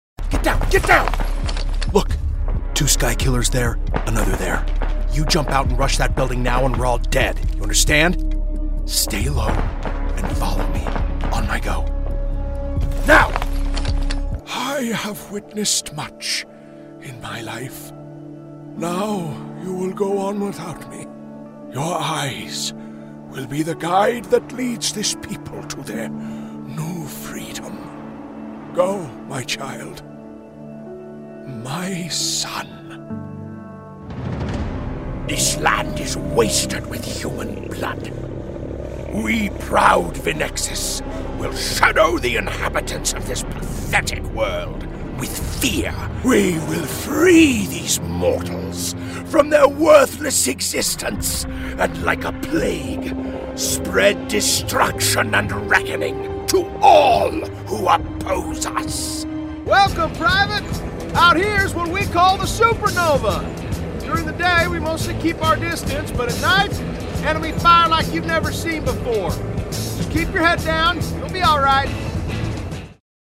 Video Game Demo